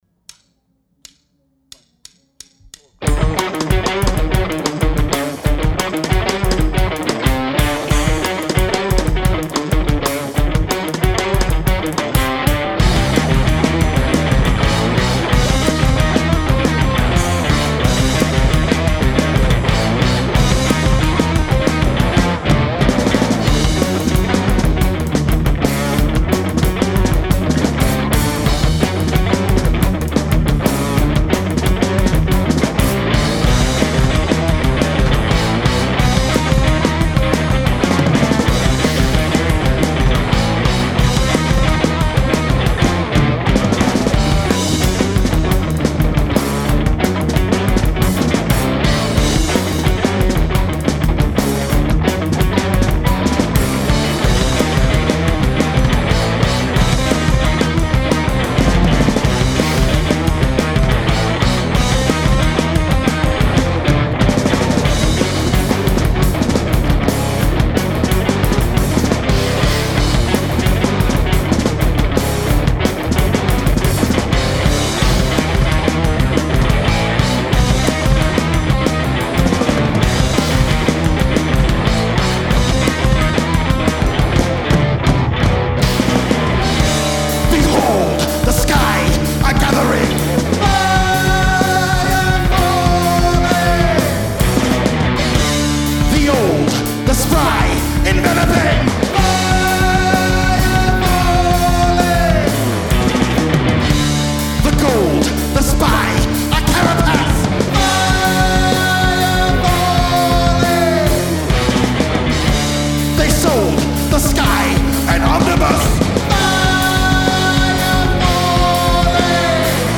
At Tank Recording Studio